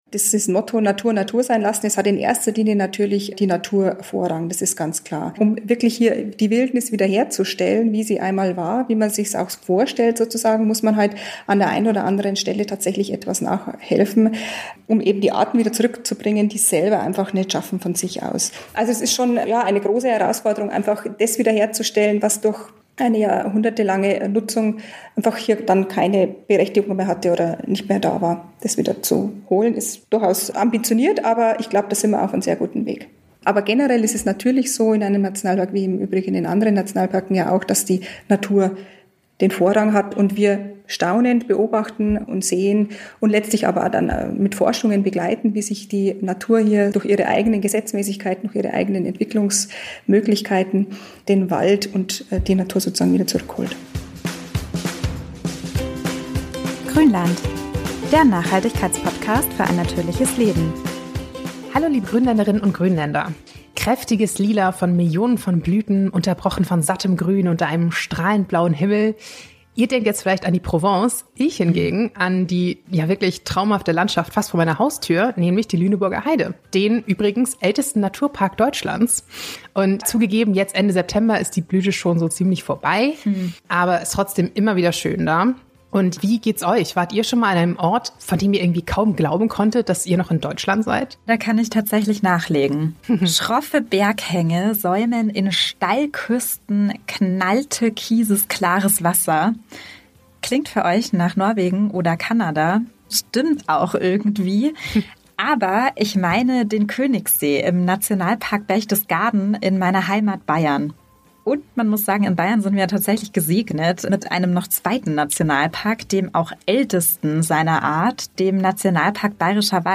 Wie sie ihre besondere Aufgabe angeht und wie sich Naturschutz, Forschung und Tourismus miteinander vereinbaren lassen, verrät sie uns im Interview